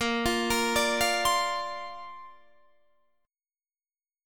Listen to A#add9 strummed